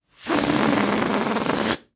Salida del aire de un globo rapido